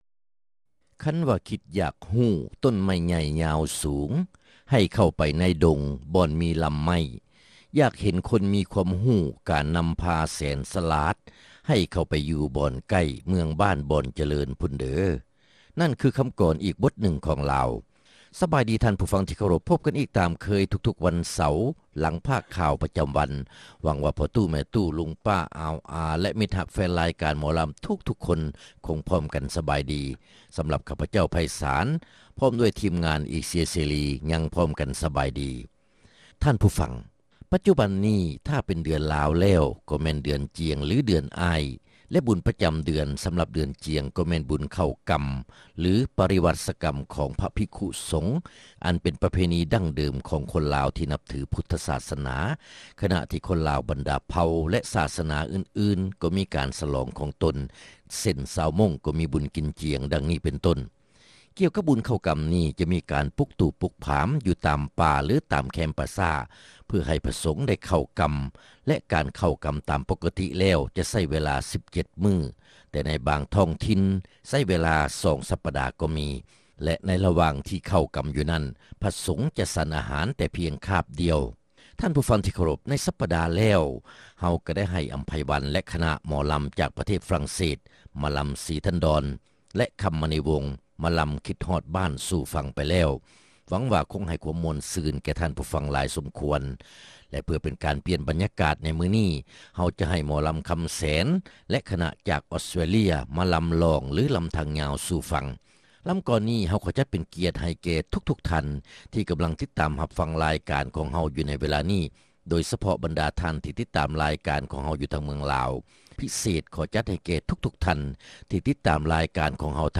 ຣາຍການໜໍລຳ ປະຈຳສັປະດາ ວັນທີ 1 ເດືອນ ທັນວາ ປີ 2006